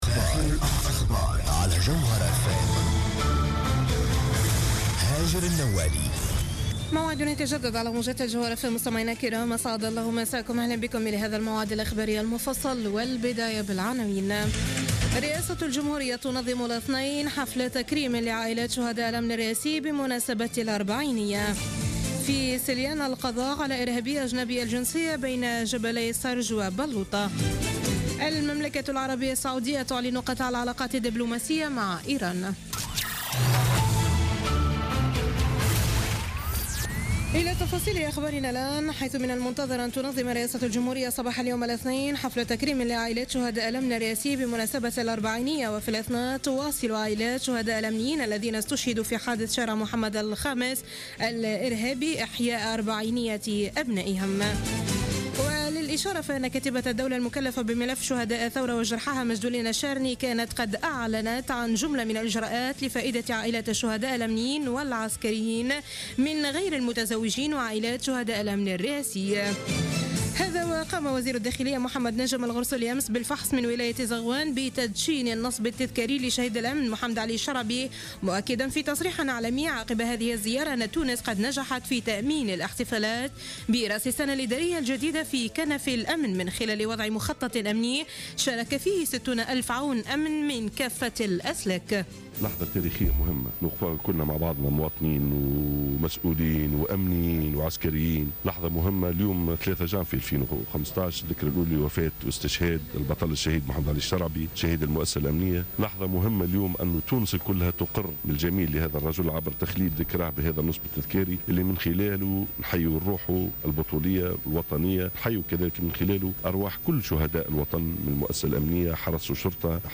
نشرة أخبار منتصف الليل ليوم الإثنين 4 جانفي 2015